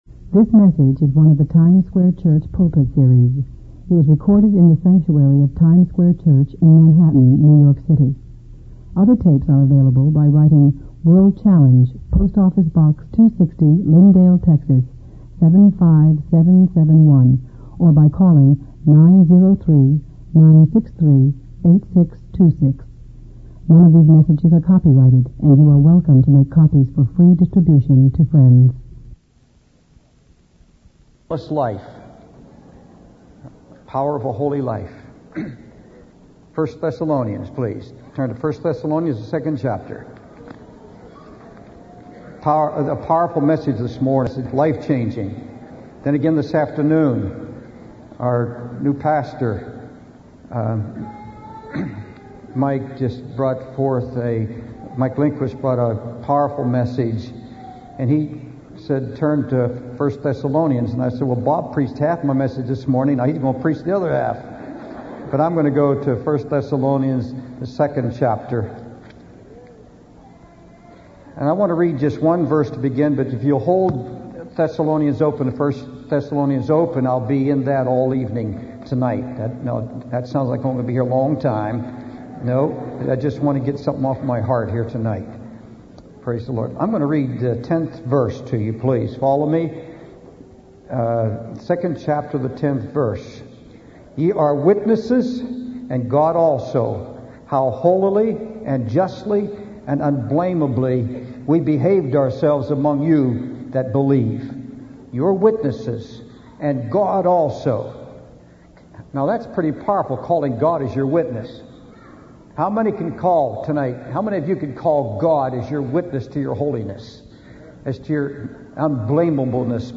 In this sermon, the speaker emphasizes the importance of living a blameless life before God.